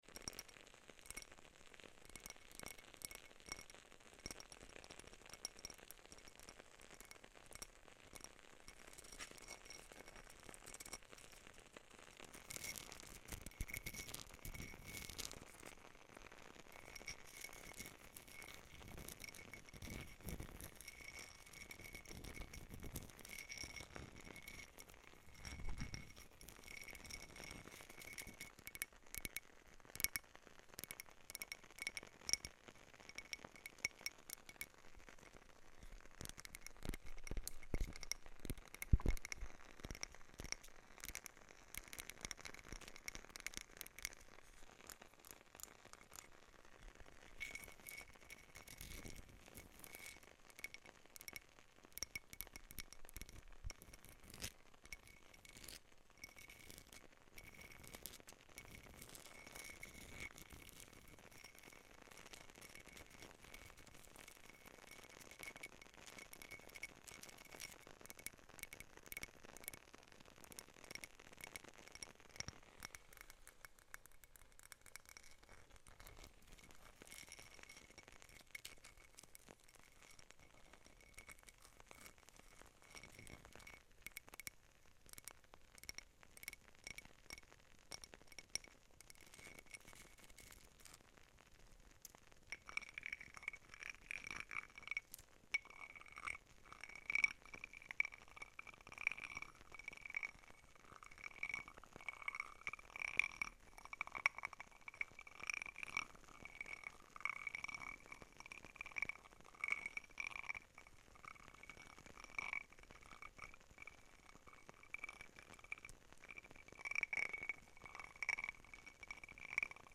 The Sound Of A Wood Sound Effects Free Download